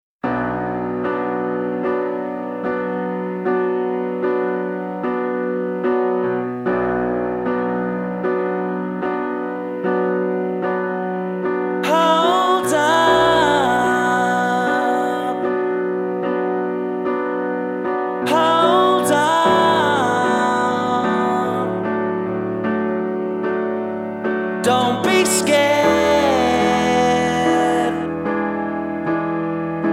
• Indie Rock